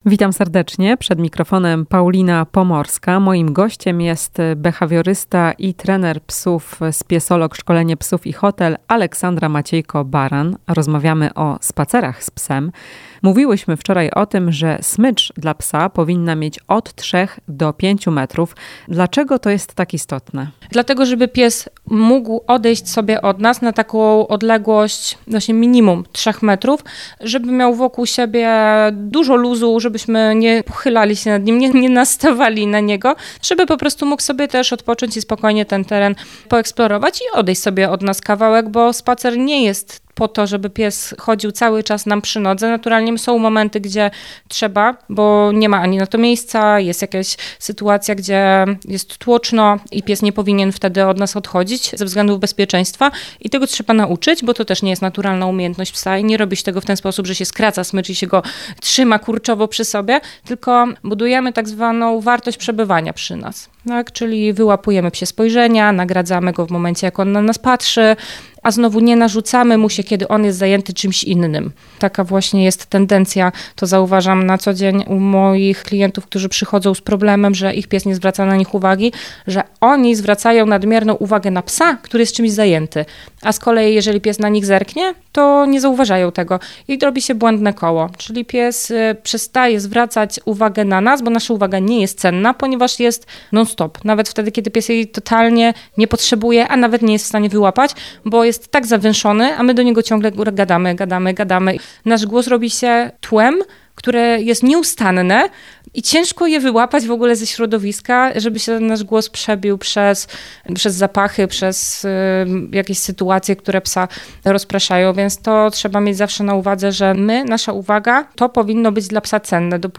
O tym w rozmowie